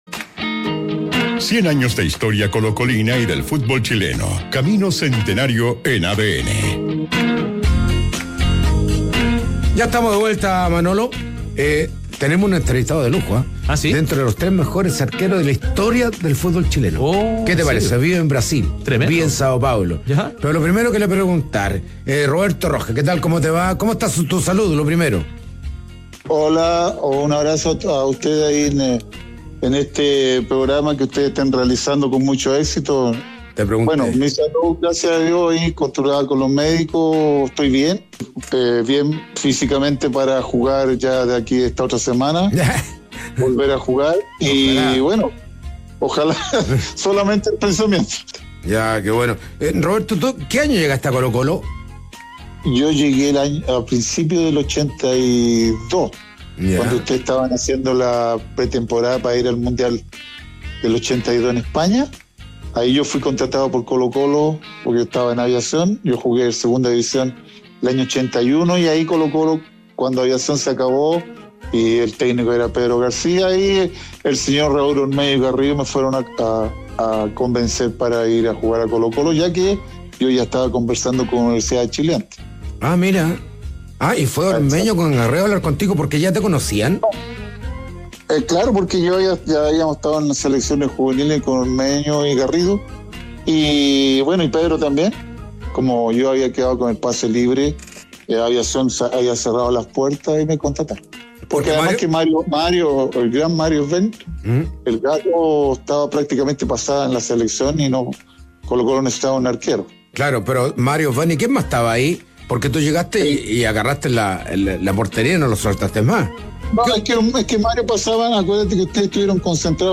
En conversación con “Camino Centenario” de ADN, el exportero también analizó críticamente el presente del fútbol sudamericano y la realización de una película basada en el “Maracanazo”.